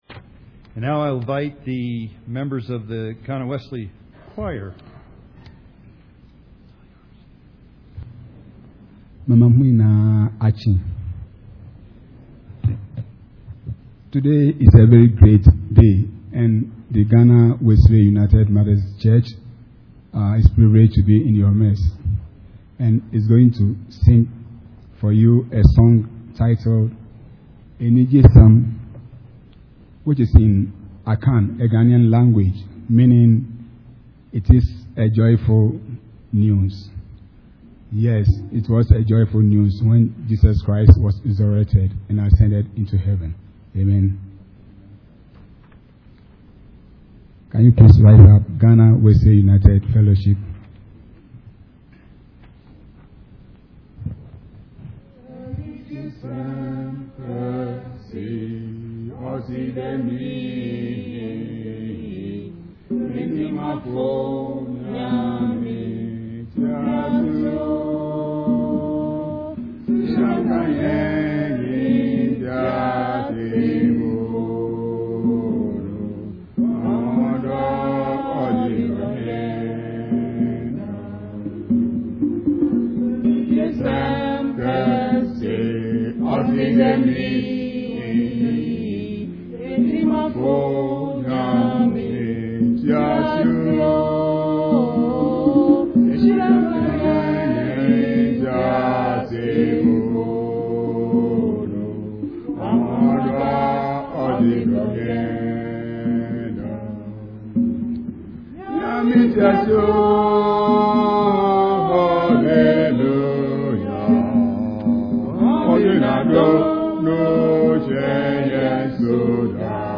Pentecost Sunday Worship Service
Song
"Enigye Sem Kese"         Ghana Wesley Fellowship Choir